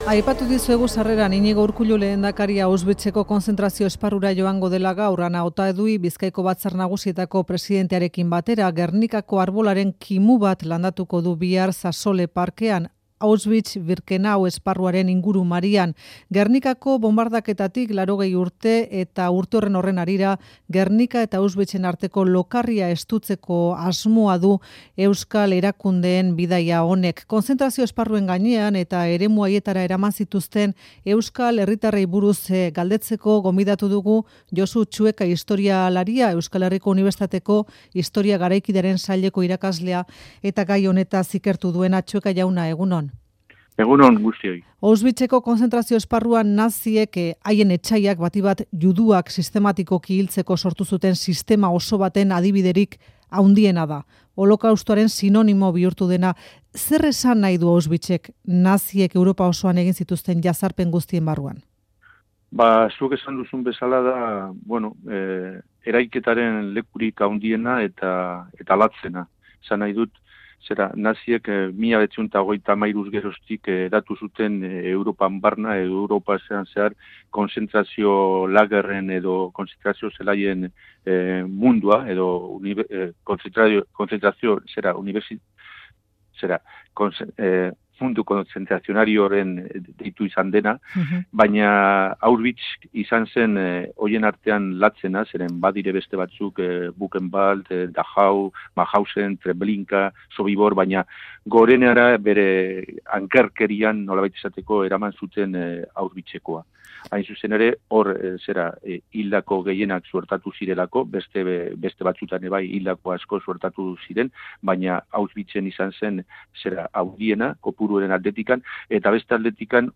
Kontzentrazio esparruei buruz, eta eremu haietara eraman zituzten euskal herritarren gainean hizketan